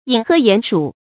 飲河鼴鼠 注音： ㄧㄣˇ ㄏㄜˊ ㄧㄢˇ ㄕㄨˇ 讀音讀法： 意思解釋： 比喻指所需求或所得極有限的人。